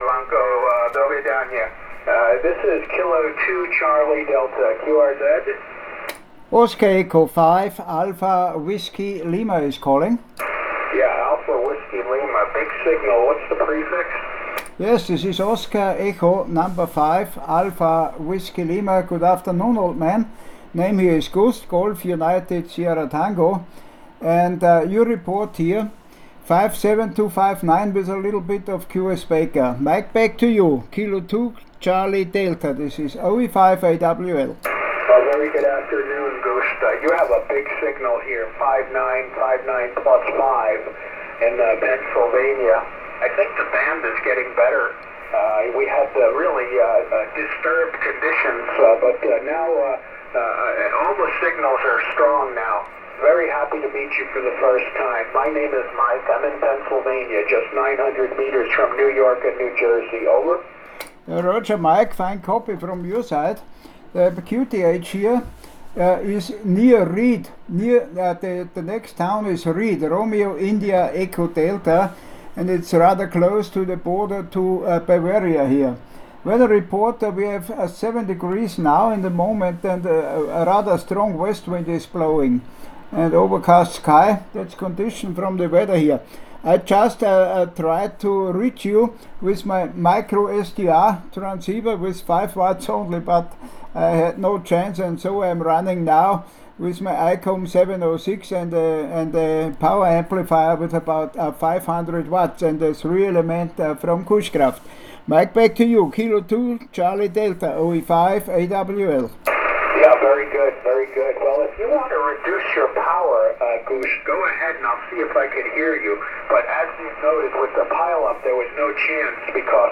Mit dem uSDR-TRX und 5 Watt 6600 km nach Amerika
Hier hört man das QSO